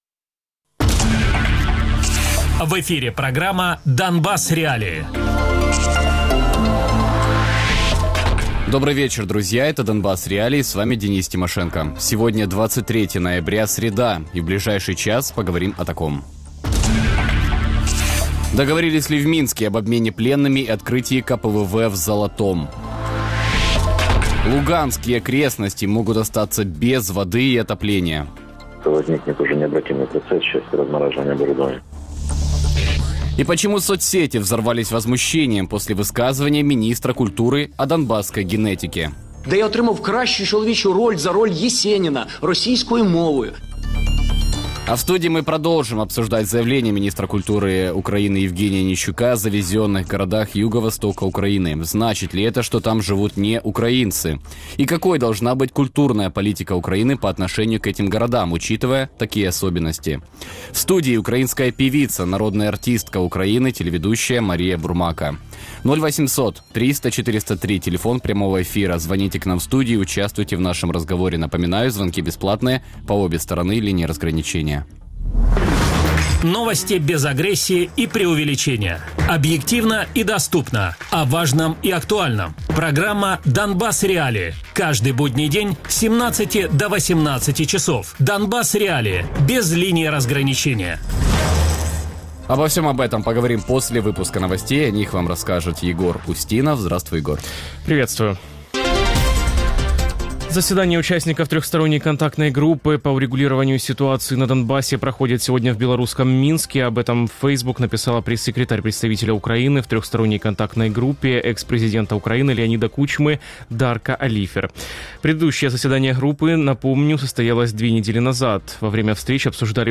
Гость: Мария Бурмака, украинская певица, народная артистка Украины, телеведущая Радіопрограма «Донбас.Реалії» - у будні з 17:00 до 18:00. Без агресії і перебільшення. 60 хвилин про найважливіше для Донецької і Луганської областей.